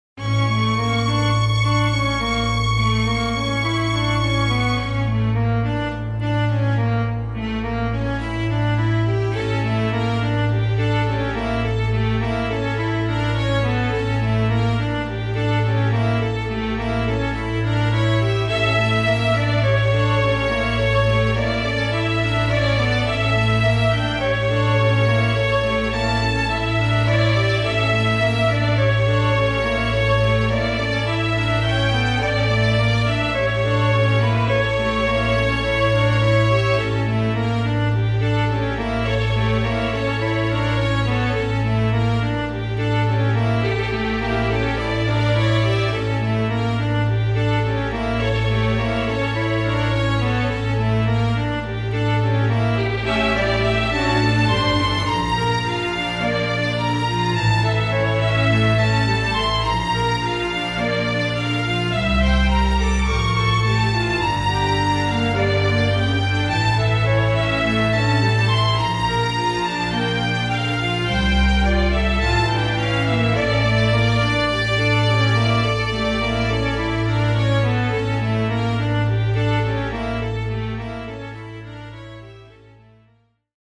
フリーBGM イベントシーン ホラー・不気味・不穏
フェードアウト版のmp3を、こちらのページにて無料で配布しています。